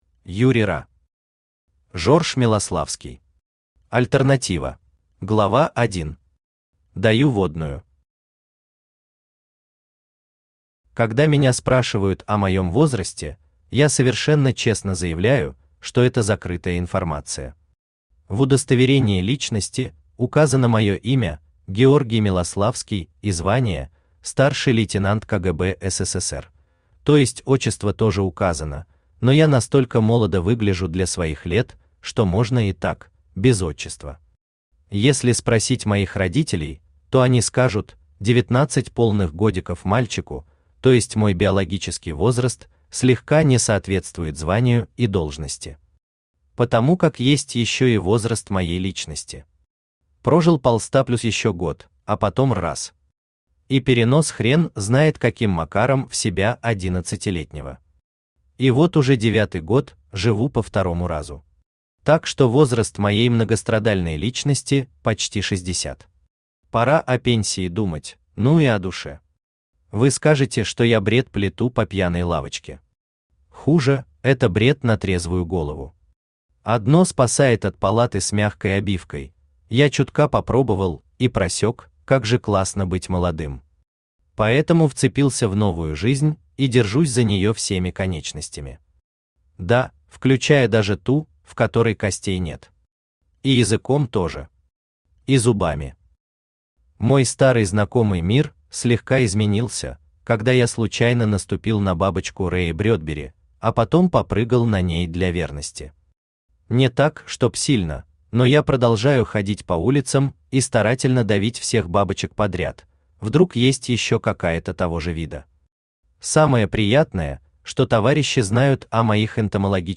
Аудиокнига Жорж Милославский. Альтернатива | Библиотека аудиокниг
Альтернатива Автор Юрий Ра Читает аудиокнигу Авточтец ЛитРес.